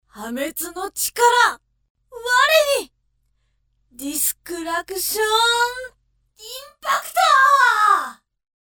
ボイスサンプル@